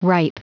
Prononciation du mot ripe en anglais (fichier audio)
Prononciation du mot : ripe